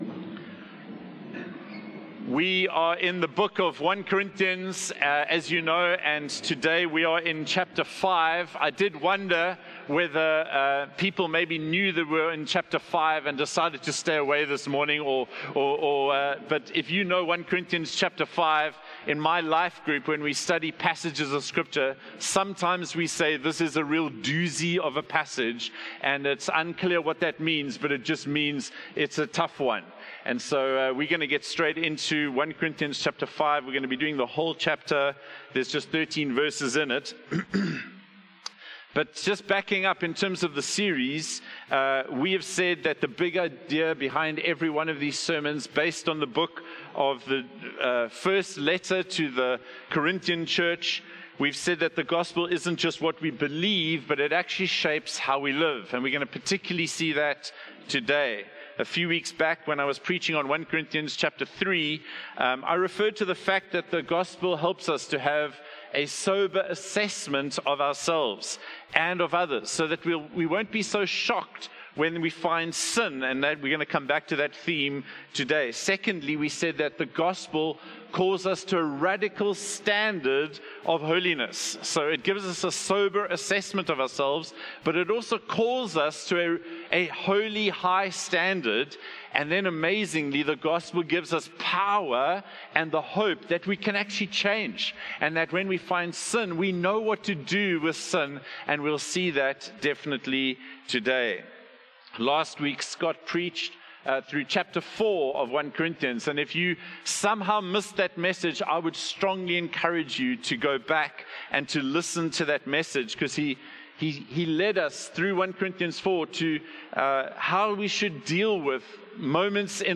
One-Hope-Sermon-6-July-2025.mp3